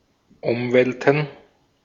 Ääntäminen
Ääntäminen Tuntematon aksentti: IPA: [ˈʊmˌvɛltn̩] IPA: [ˈʊmˌvɛltən] Haettu sana löytyi näillä lähdekielillä: saksa Käännöksiä ei löytynyt valitulle kohdekielelle. Umwelten on sanan Umwelt monikko.